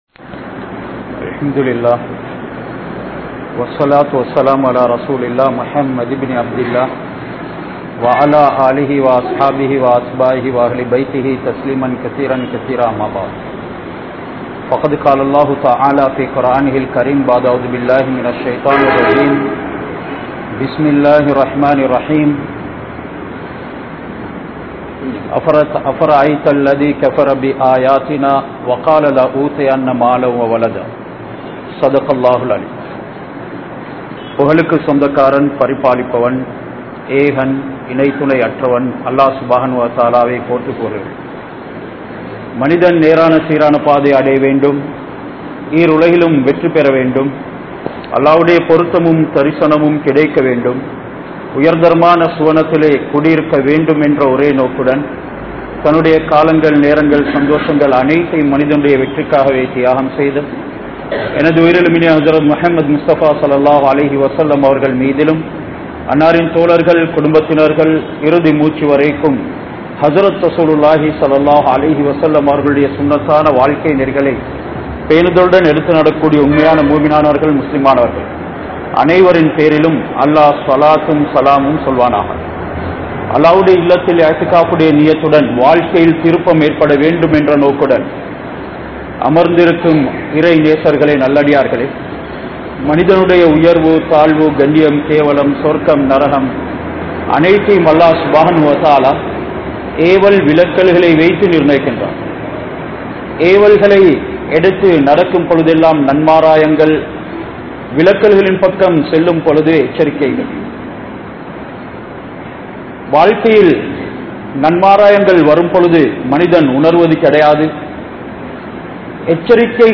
Neethiyaaha Nadavungal (நீதியாக நடவுங்கள்) | Audio Bayans | All Ceylon Muslim Youth Community | Addalaichenai
Kurunegala, Mallawapitiya Jumua Masjidh